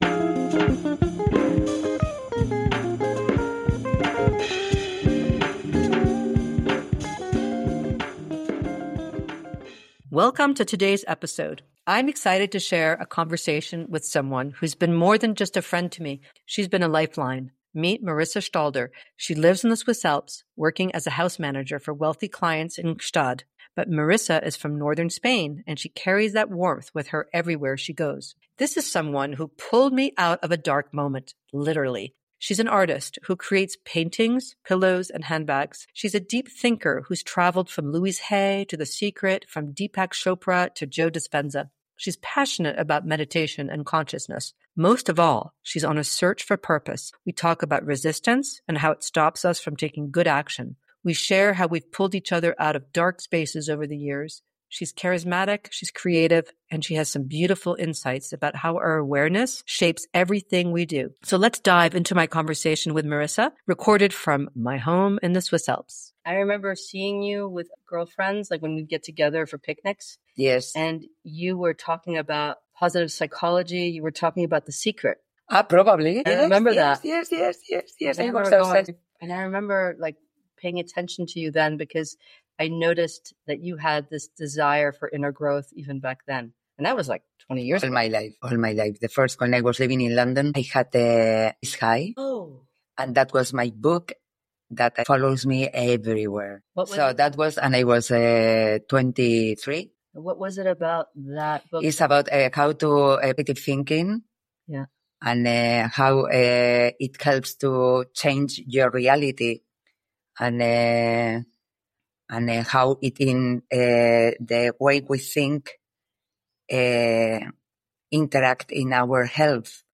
A soulful conversation about choosing love over fear, and the daily practice of becoming who you truly are.